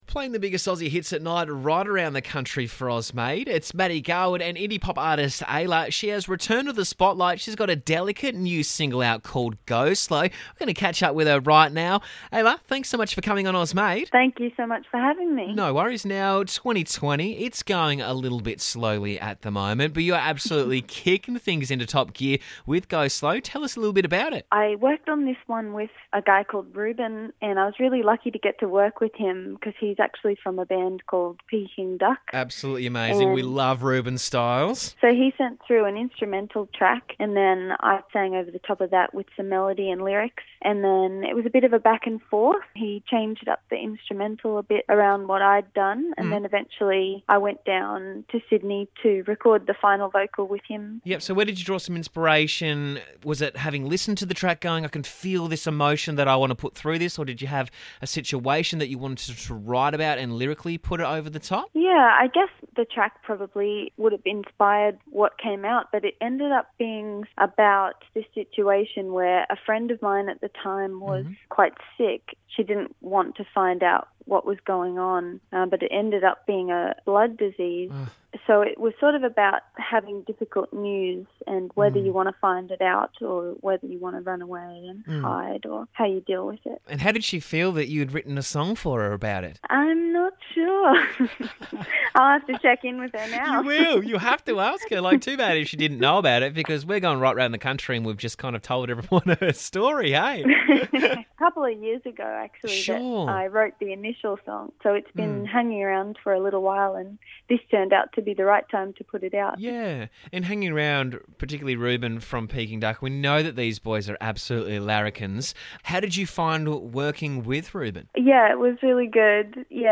Indie-pop
bold and flawless vocals